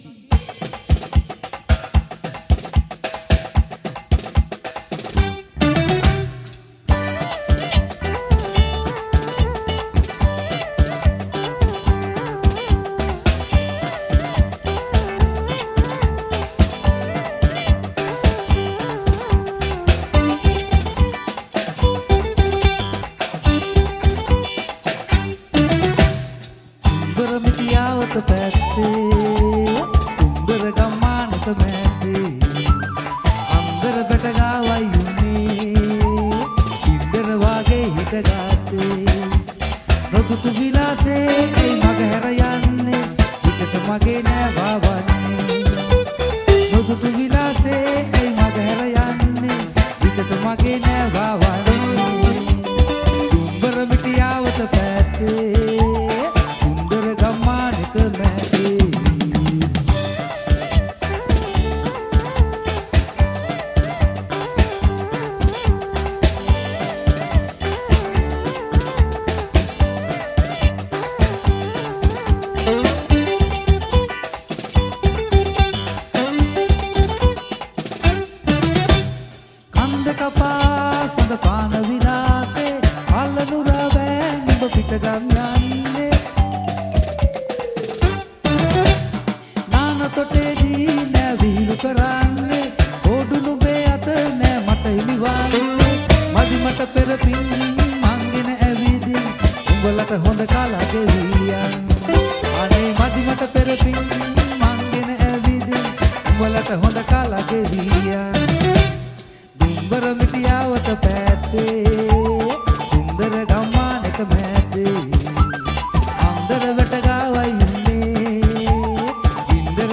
Musical Group
Musical Show